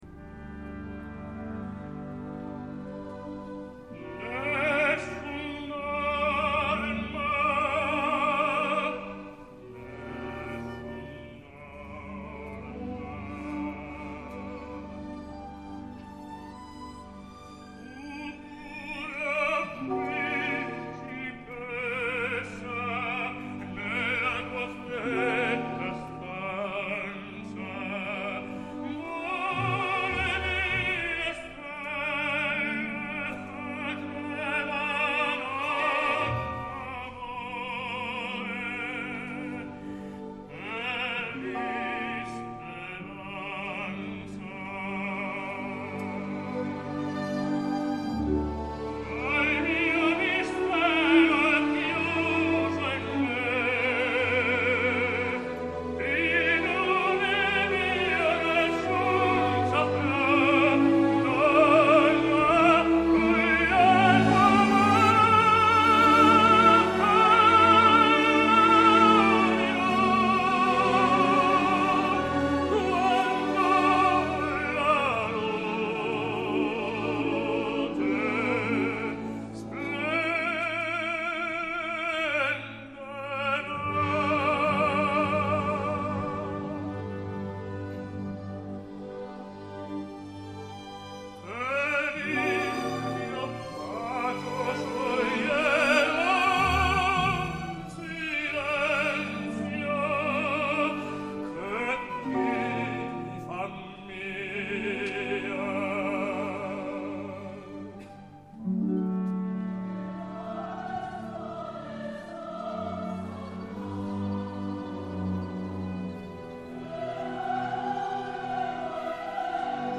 : I brani qui presentati sono tutti tratti da  registrazioni amatoriali , spesso realizzate da amici o colleghi Ne  è vietata la divulgazione con qualsiasi mezzo o utilizzo a  scopo commerciale.
Here below you can find some arias performed by  tenor  Antonello  Palombi.